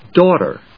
daugh・ter /dˈɔːṭɚ‐tə/
gh発音しない
• / dˈɔːṭɚ(米国英語)